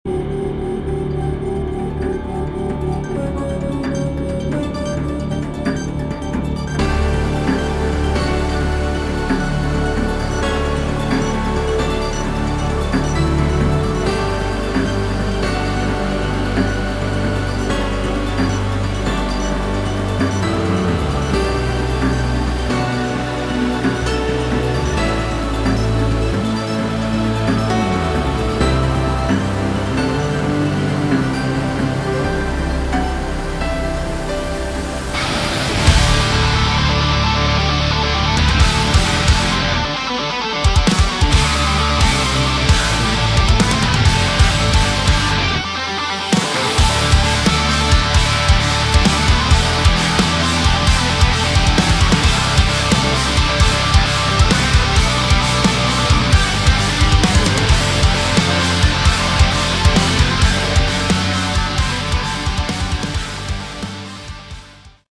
Category: Melodic Metal / Prog Metal
vocals
guitars
bass
drums
keyboards